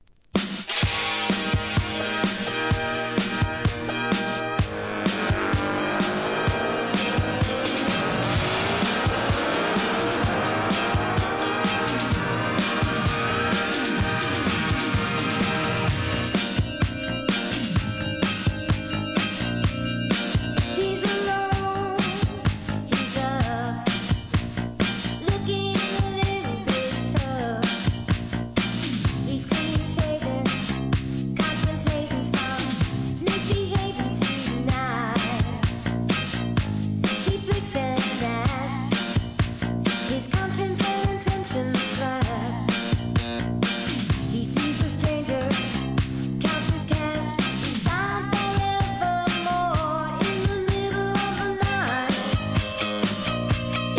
SOUL/FUNK/etc...
エレクトロDISCO!